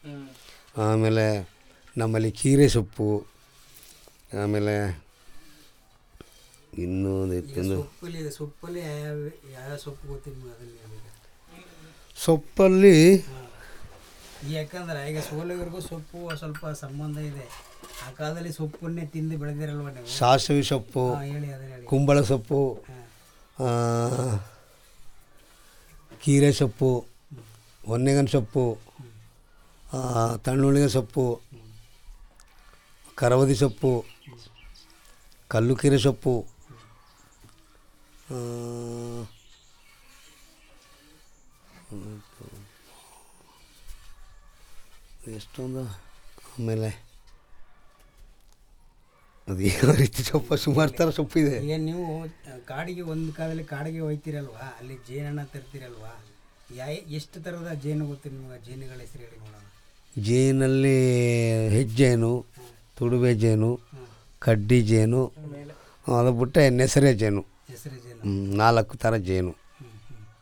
Elicitation of words about Leafy Vegetable as edible greens and honey bee